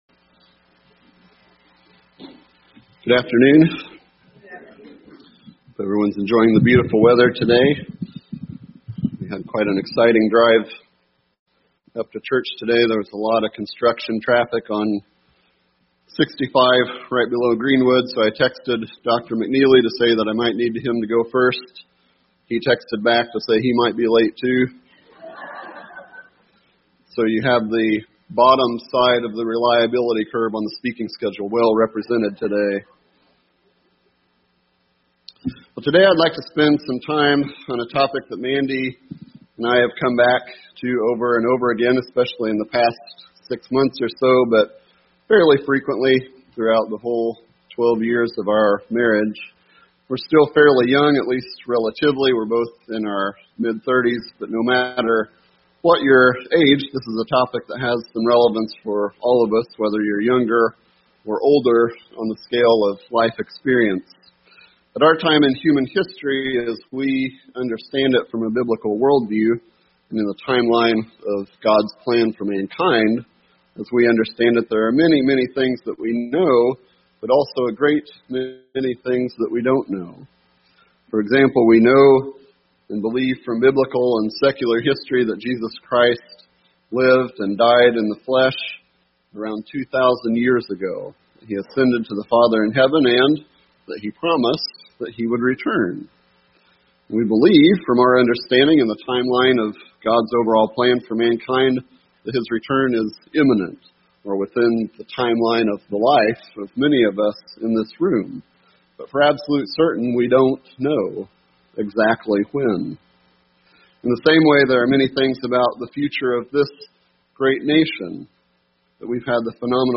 Given in Indianapolis, IN
UCG Sermon Notes Notes: We believe that Christ’s return is imminent.